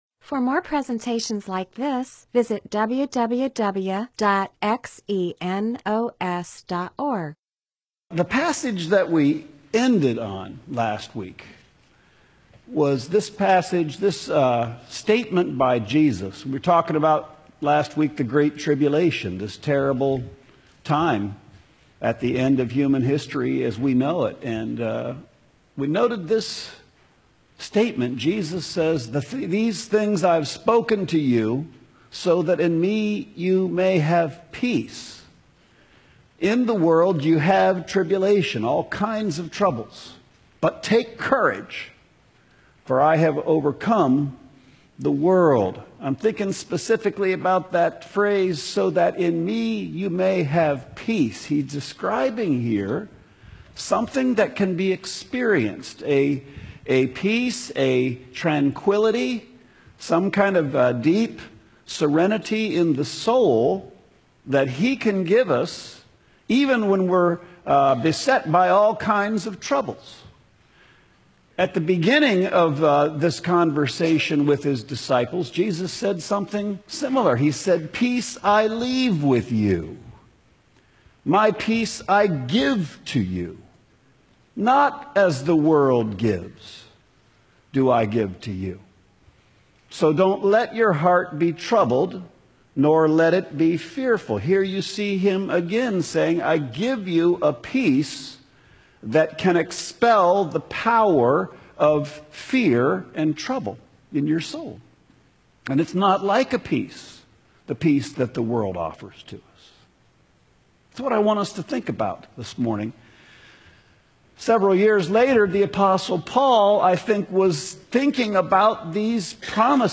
MP4/M4A audio recording of a Bible teaching/sermon/presentation about Philippians 4:6-7.